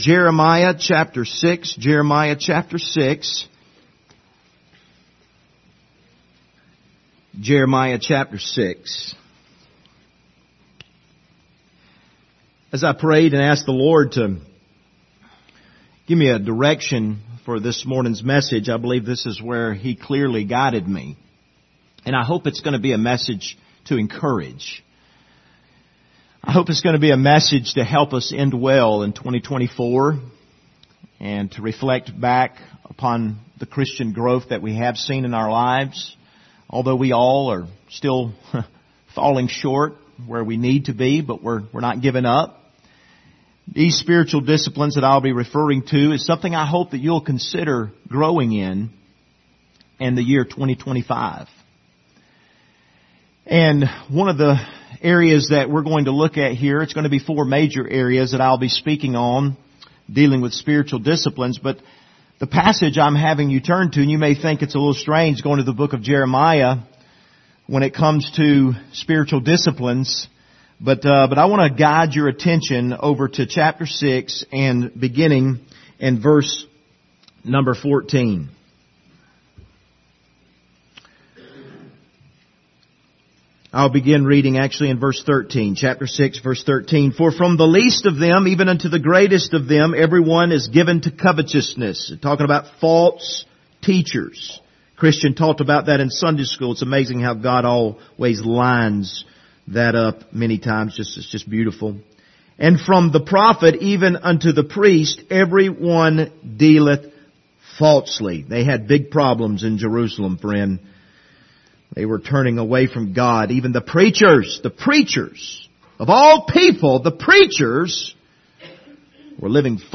Passage: Jeremiah 6:13-17 Service Type: Sunday Morning